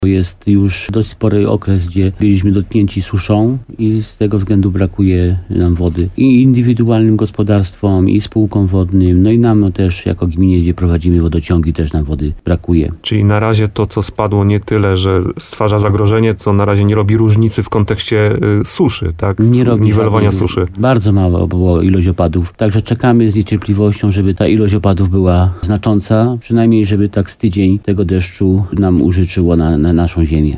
Wójt Jan Skrzekut mówił w programie Słowo za słowo, że opadów jak na razie było zbyt mało.